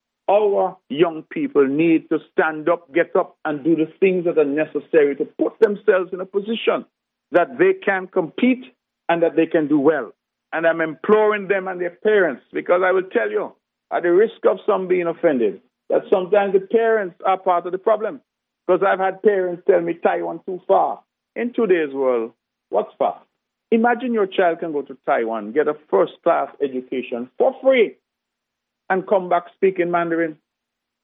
Hon. Mark Brantley.